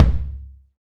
BR Tom Lo.WAV